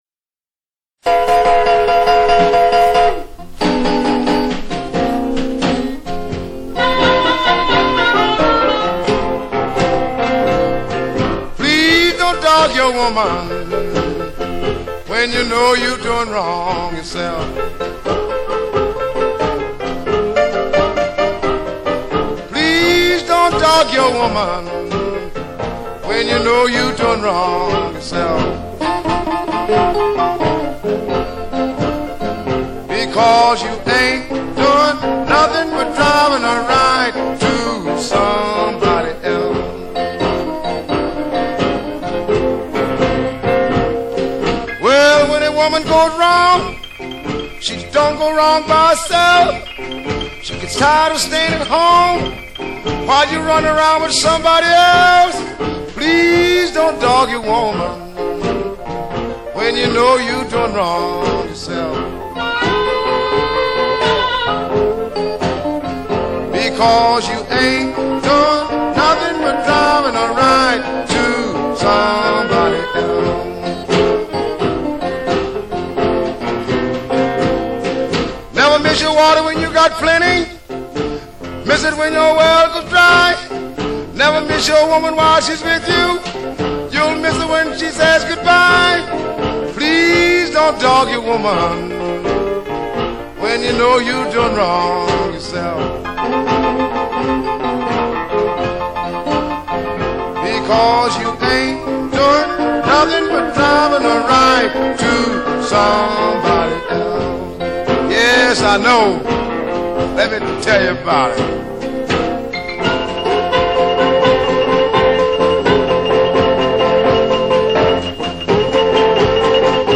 moderate tempo tune with moralizing lyrics